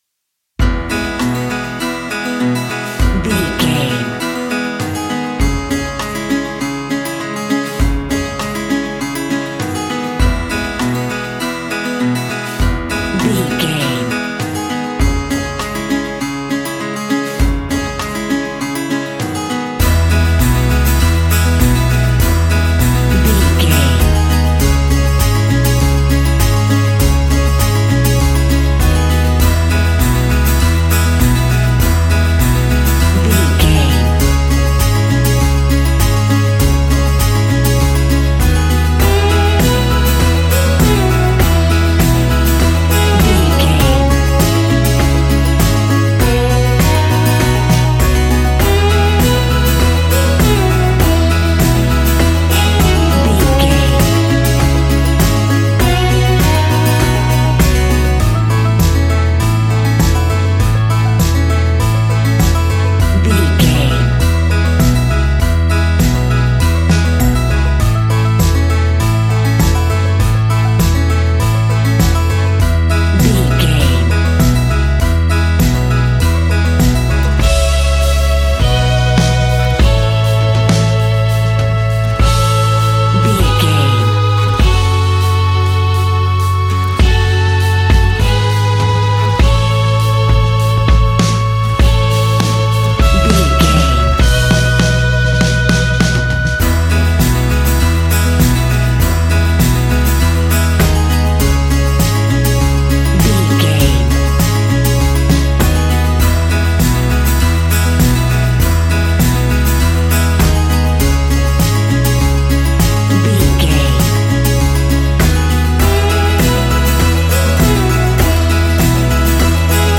Uplifting
Ionian/Major
acoustic guitar
mandolin
ukulele
double bass
accordion